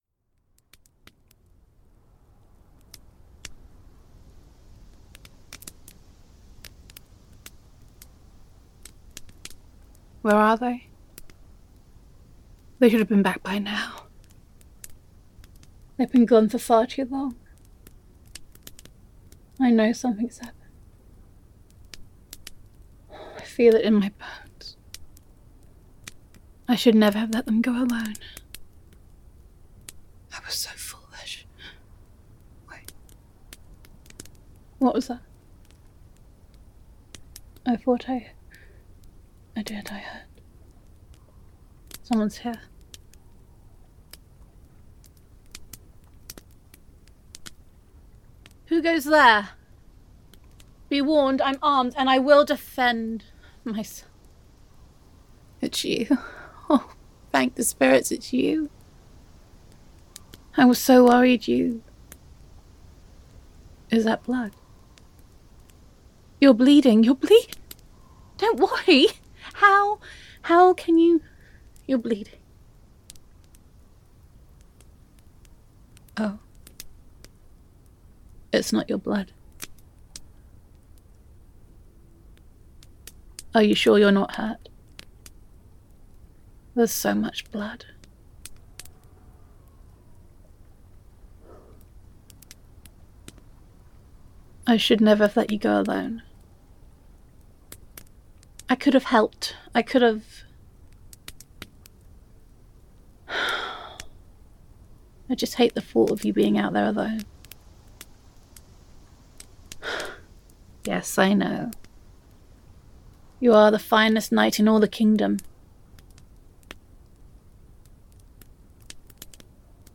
[F4A] Beloved Knight [Princess Roleplay][Comfort][Love Confession][Fantasy Roleplay][Come Sit by the Fire][Put Your Head in My Lap][Gender Neutral][A Sweet Princess Comforts Her Weary Knight]